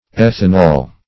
ethanol \eth"an*ol\ ([e^]th"[a^]n*[add]l), n. (Chem.)